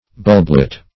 Bulblet \Bulb"let\, n. [Bulb,n.+ -let.]